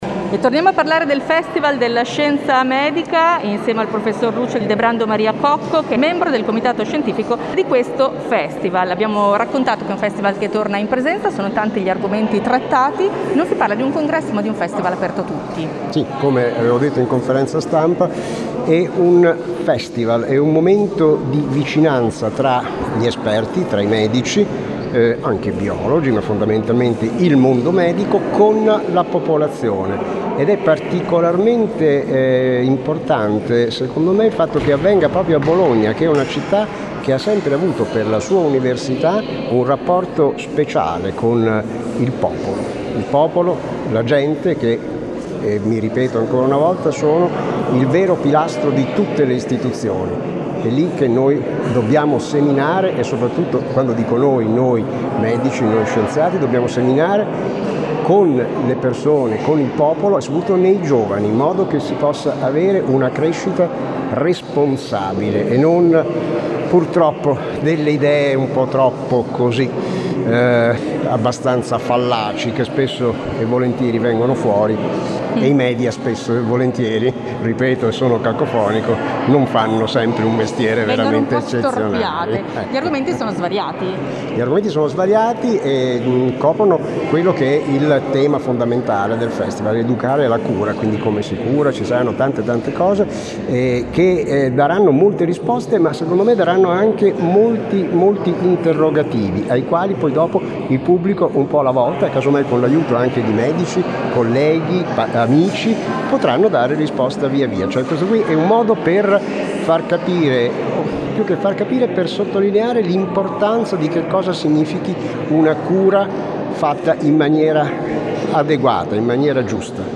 l’intervista a